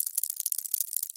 В коллекции есть разные варианты: от шороха лапок до стрекотания.
Шорох шагов таракана